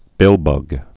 (bĭlbŭg)